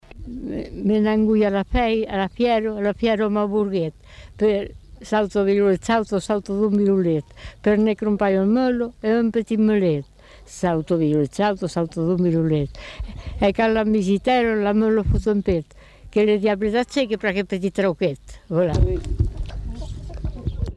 Aire culturelle : Savès
Genre : chant
Effectif : 1
Type de voix : voix de femme
Production du son : récité